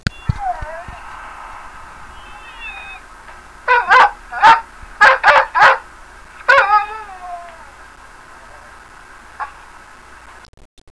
an image of your pup, but to hear a recording of them as well.
A great keepsake of your puppy's voice or the loving voice of a friend who has passed on.